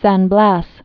(săn blăs, sän bläs), Gulf of